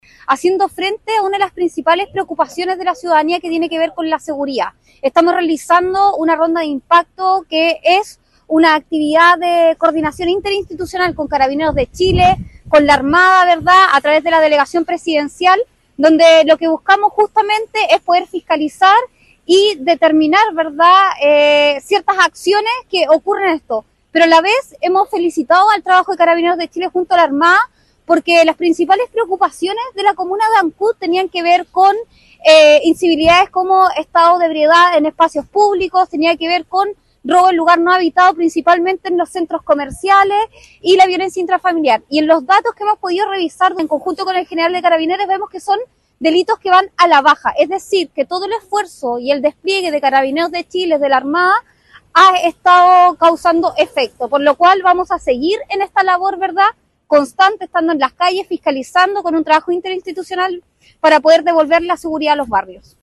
En la estrategia participó Carabineros y la Policía Marítima, quienes se desplegaron en puntos estratégicos de Ancud, con el objetivo de combatir la sensación de inseguridad y prevenir delitos priorizados en el Sistema Táctico de Operación Policial, destacó  la delegada regional, Giovanna Moreira.